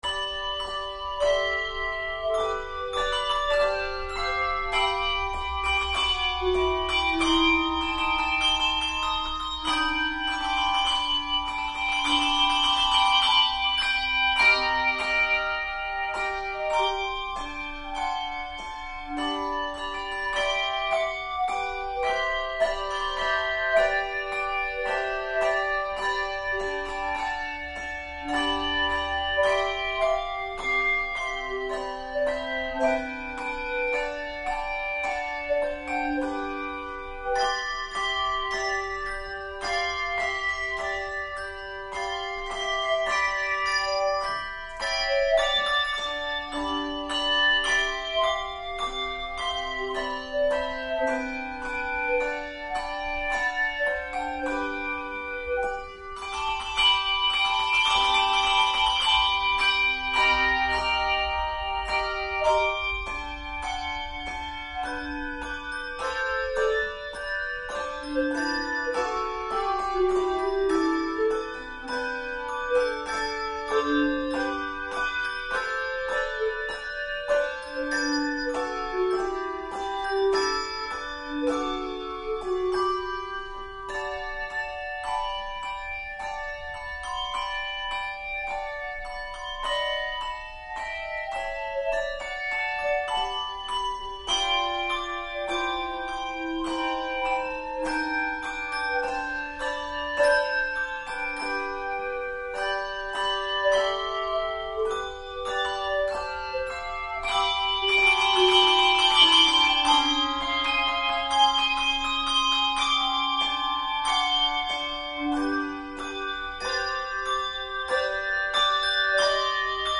Handbell Quartet
Genre Sacred